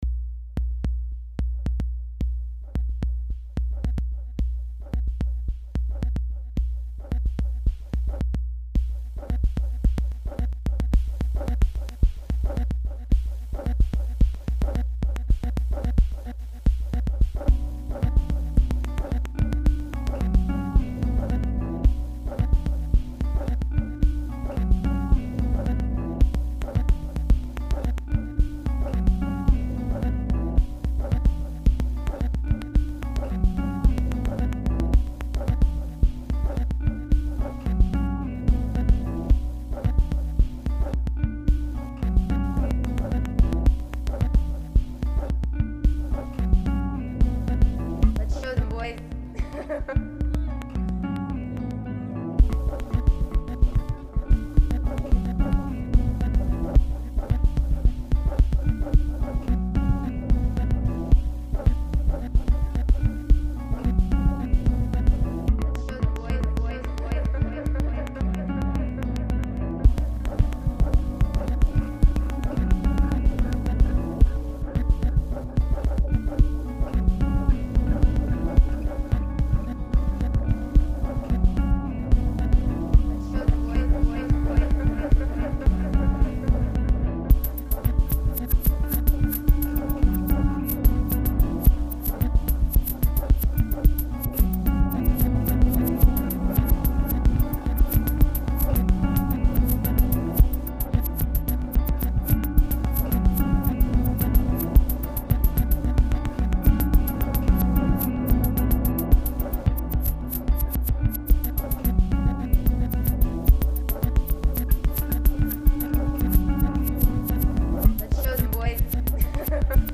Official and un-official remixes
remix